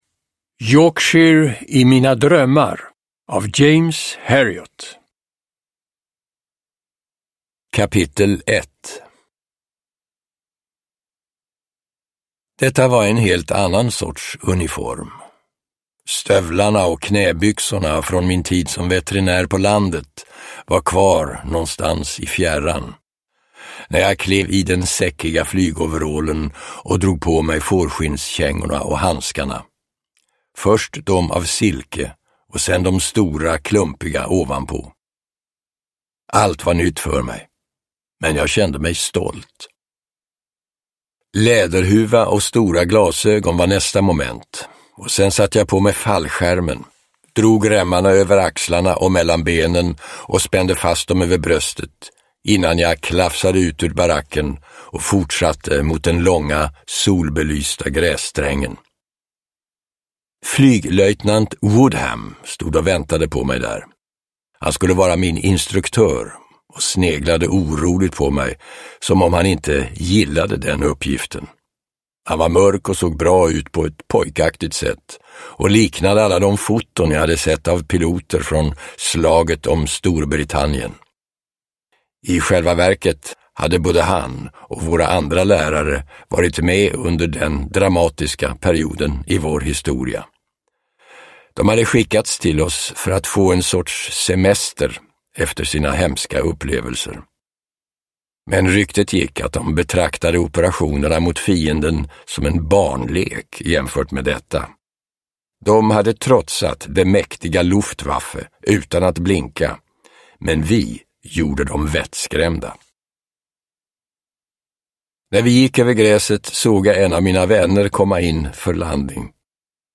Yorkshire i mina drömmar – Ljudbok – Laddas ner
Uppläsare: Björn Granath